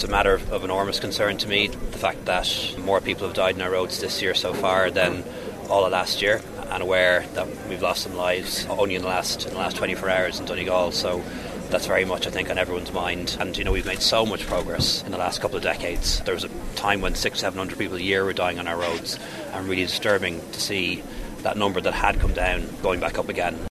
Taoiseach Leo Varadkar says it’s disturbing to see the figures rising again: